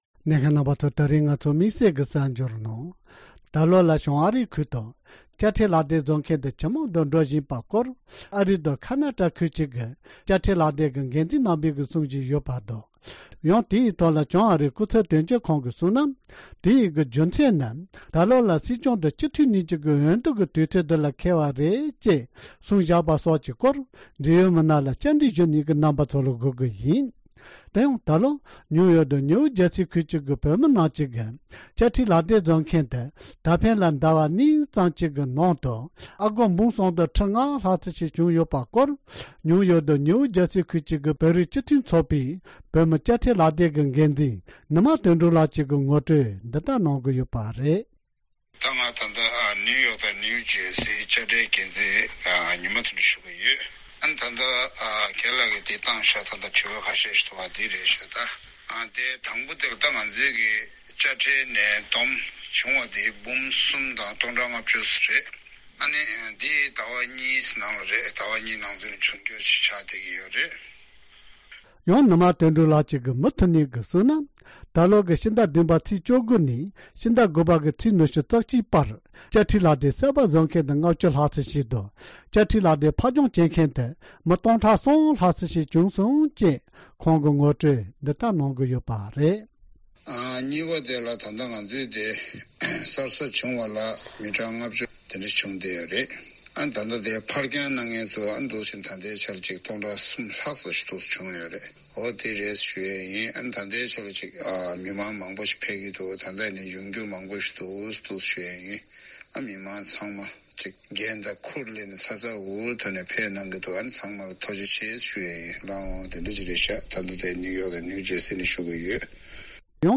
སྒྲ་ལྡན་གསར་འགྱུར།
འབྲེལ་ཡོད་མི་སྣ་ལ་བཅའ་འདྲི་ཞུས་པ་དེ་གསན་རོགས་གནང་།